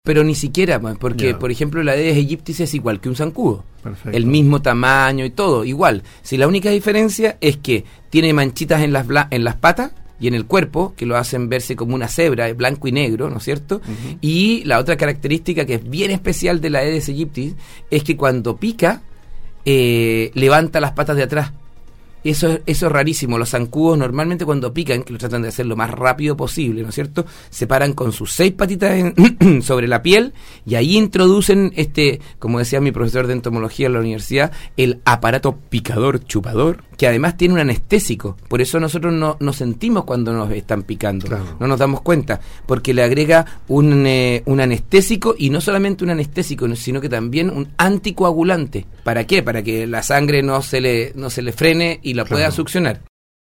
estuvo en los estudios de Nostálgica, donde se refirió a la importancia de reconocer este insecto que transmite enfermedades virales como la fiebre amarilla, zika, chikungunya y dengue.